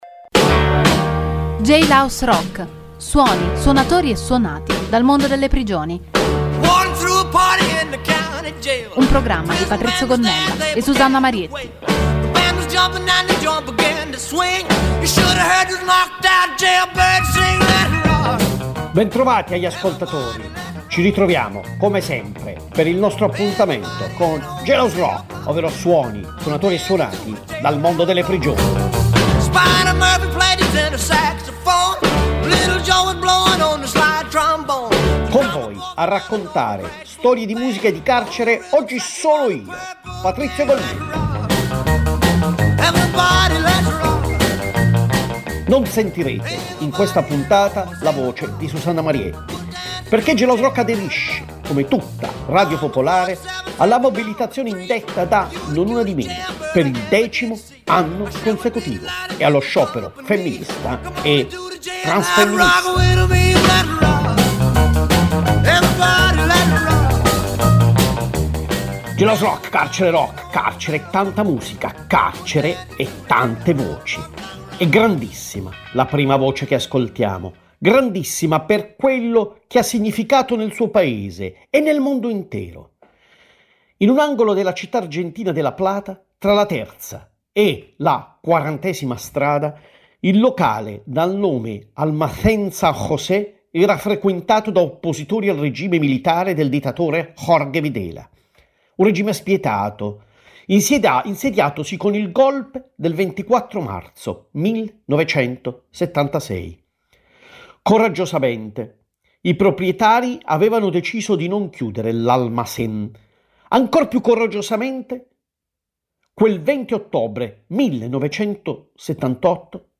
il programma include storie e suoni dal mondo delle prigioni, con la partecipazione di detenuti dei carceri di Rebibbia e Bollate che realizzano un Giornale Radio dal Carcere e cover di artisti.